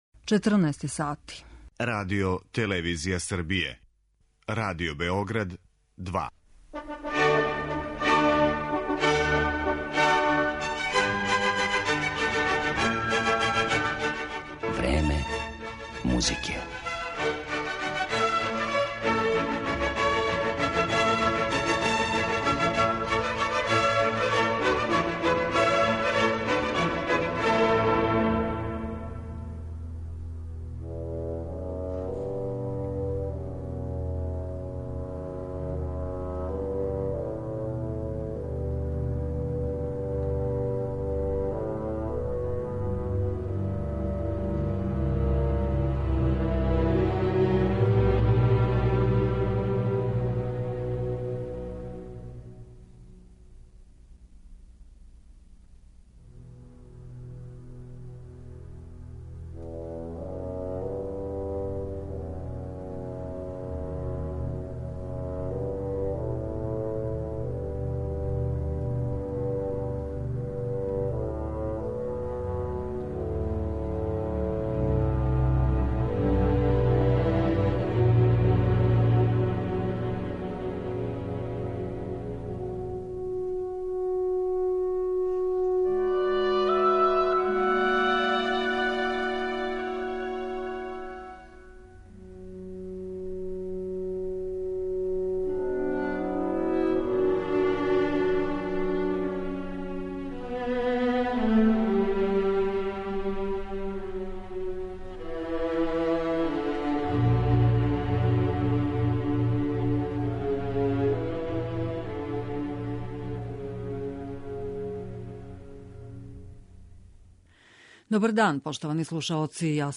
Фагот, туба, виола, контрабас